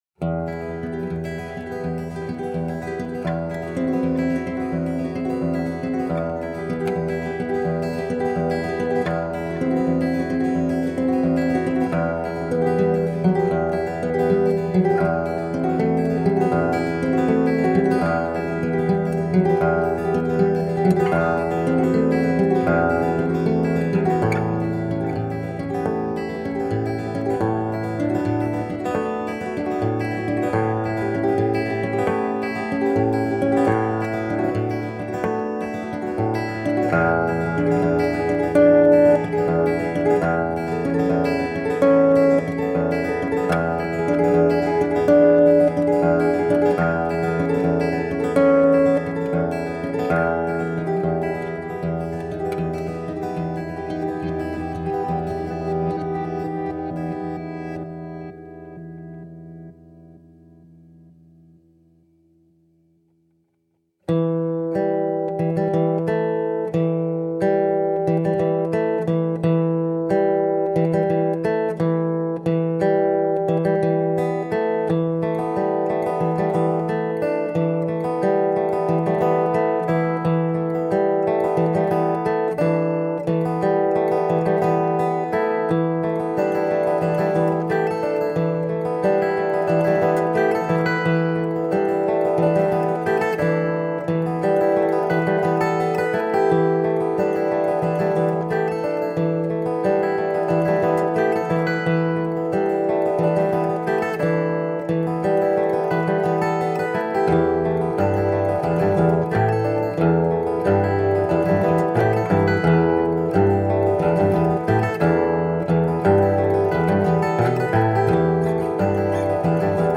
Looped acoustic guitar layers.